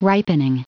Prononciation du mot ripening en anglais (fichier audio)
Prononciation du mot : ripening